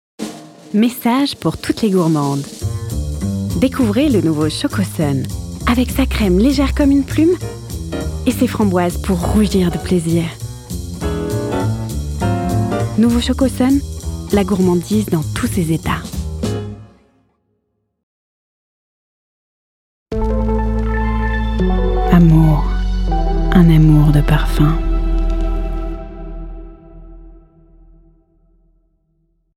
Voix off
Medley Pub TV - Pub Chocosun & Signature Parfum Amour - non diffusé
J'ai une voix jeune, dynamique, solaire qui saura se mettre au service de vos projets et apporter selon vos besoins de la naïveté, de l'apaisement, du fun ou de la détermination.
voixféminine